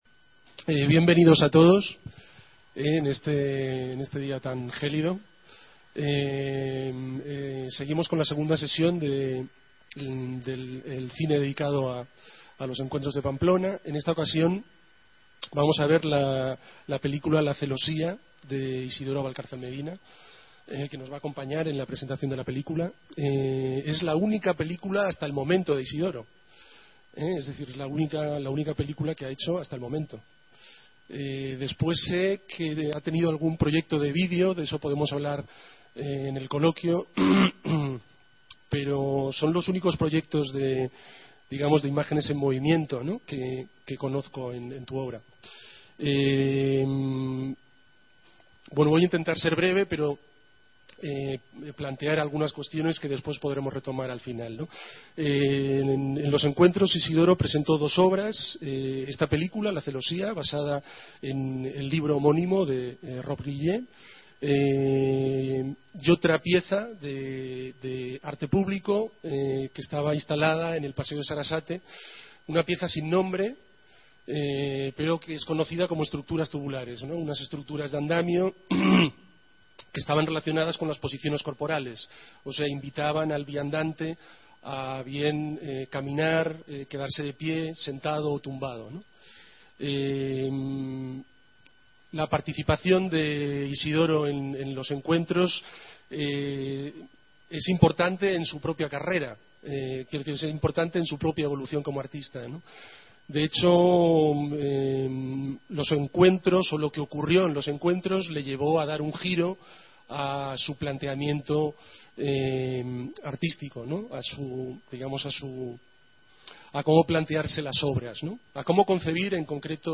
Isidoro Valcárcel Medina, en conversación